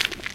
default_gravel_footstep.4.ogg